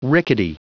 Prononciation du mot rickety en anglais (fichier audio)
Prononciation du mot : rickety